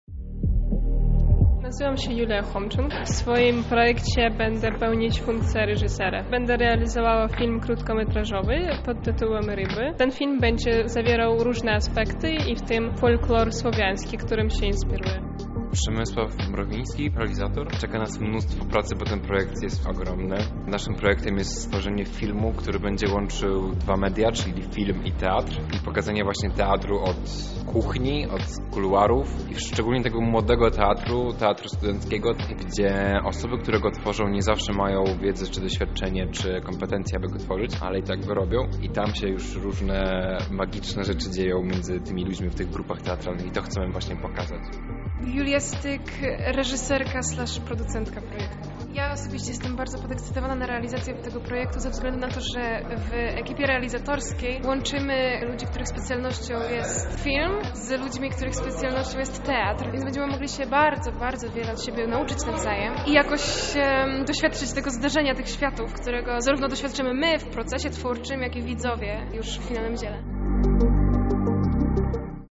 Na piątkowej Gali w Chatce Żaka zostało przyznanych 19 stypendiów.